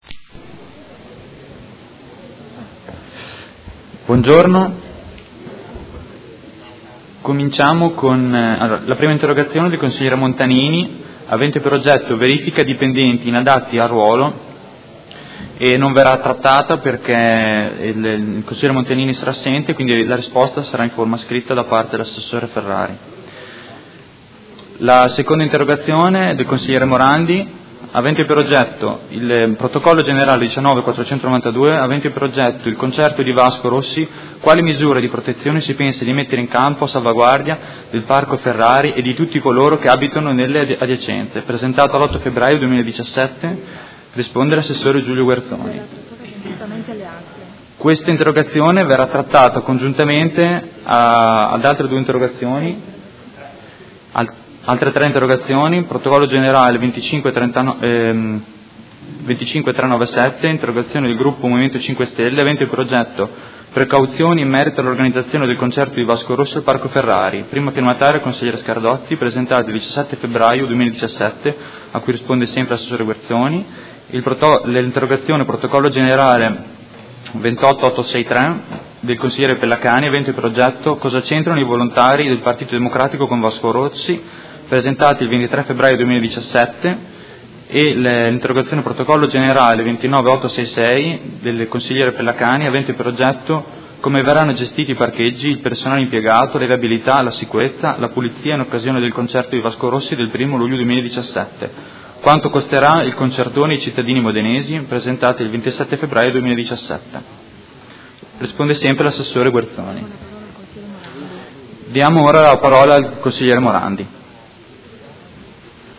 Seduta del 30/03/2017. Comunicazione del Presidente su risposta scritta a Interrogazione del Consigliere Montanini (CambiAMOdena) avente per oggetto: Verifica dipendenti inadatti al ruolo